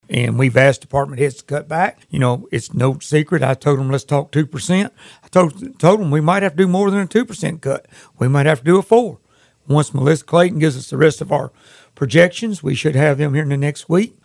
He adds there are potential cuts for departments.